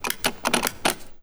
toggles.wav